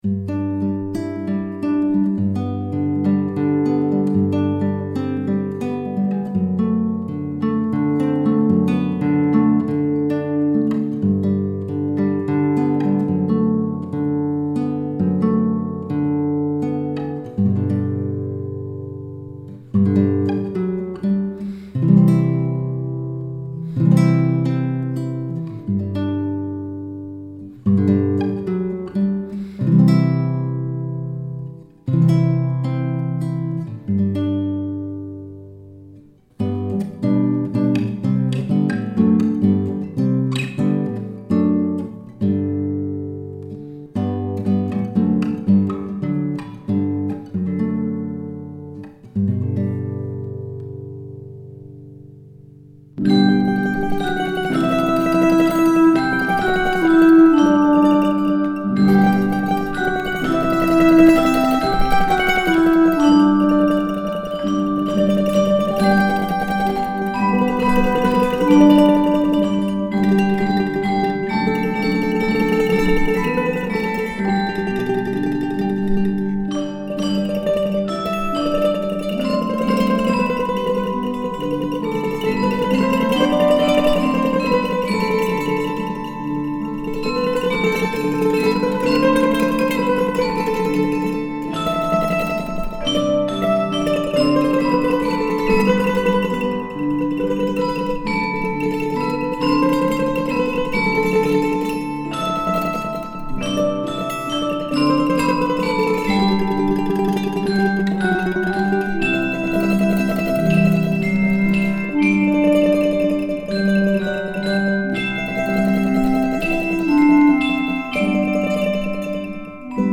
Il suono delle campanine – Le scuole campanarie della FCB
Esegue la Scuola Campanaria di Roncobello.